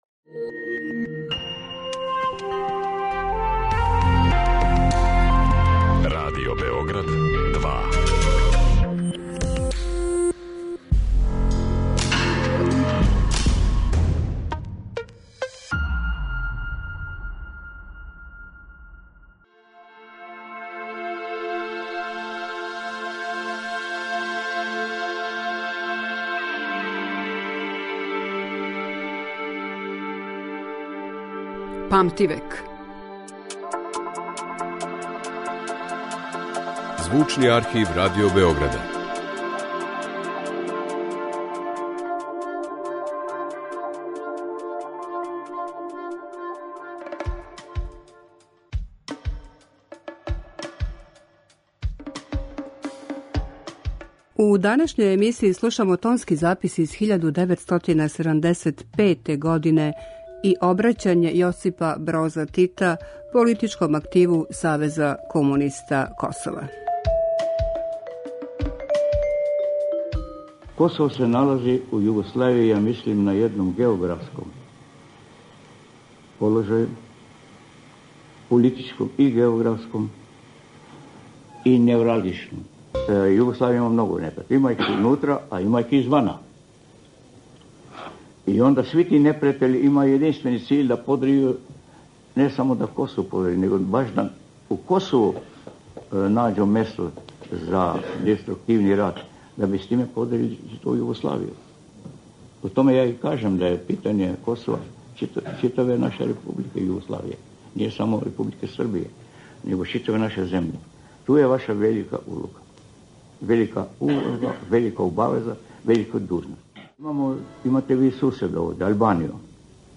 Седамдесетих се у Београду говорило да ће се после маршалове смрти почети са разбијањем Југославије, а то је Тито наслутио априла 1975. године. Слушамо тонски запис снимљен приликом посете јужној српској покрајини.